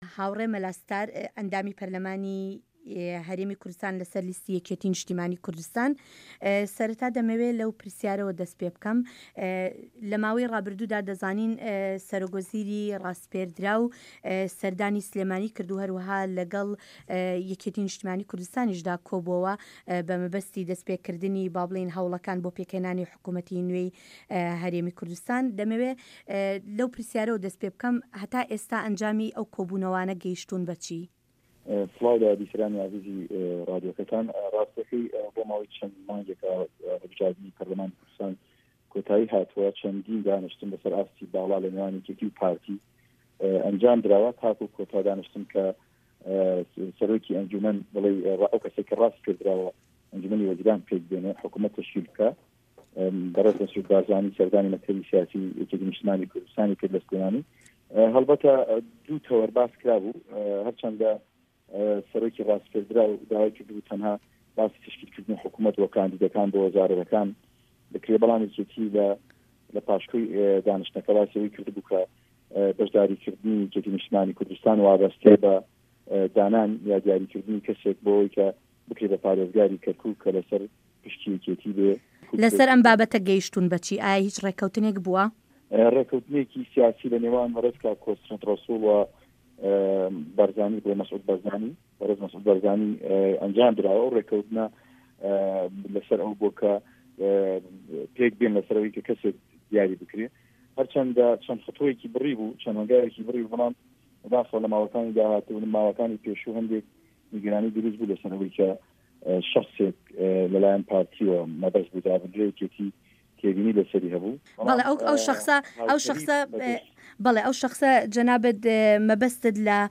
وتووێژ لەگەڵ هاوڕێ مەلا ستار